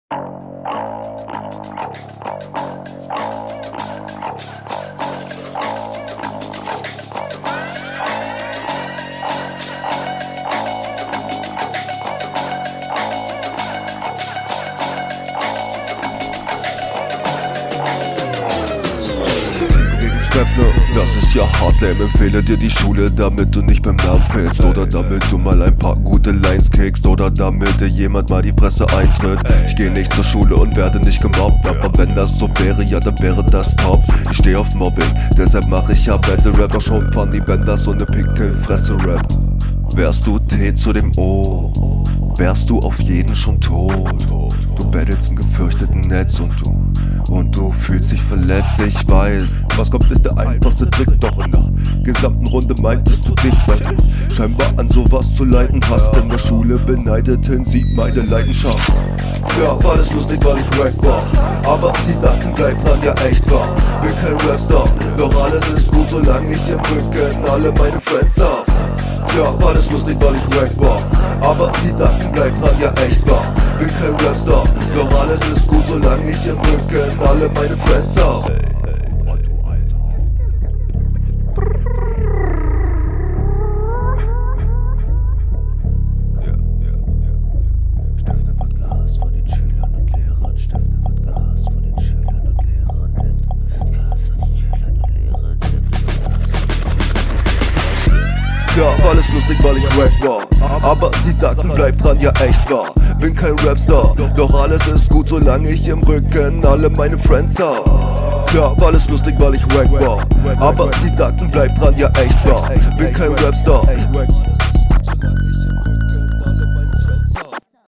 Habe jetzt wenigstens erwartet, dass du den vibe ungefähr annimmst, dem ist aber leider nicht …